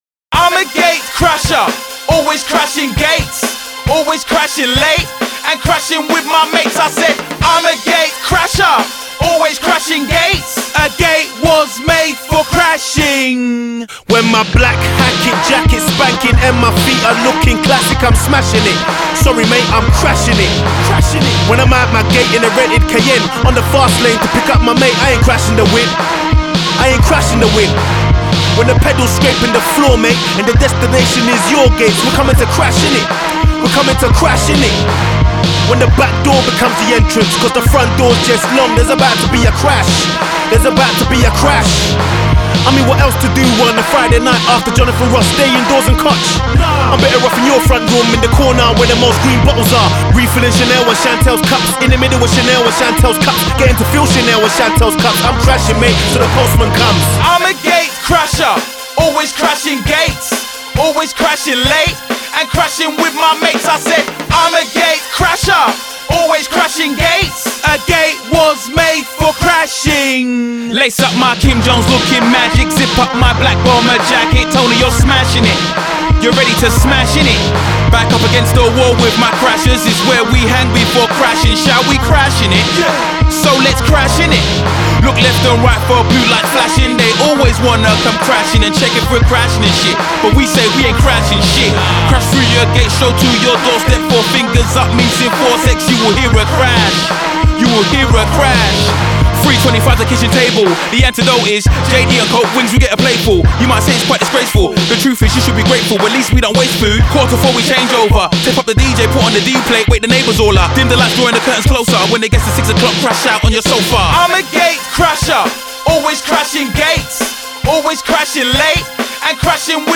Жанр: uk hip-hop / grime